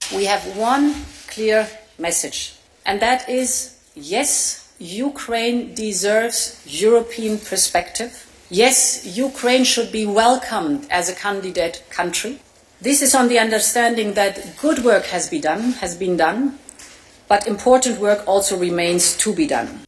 President von der Leyen announced the news earlier: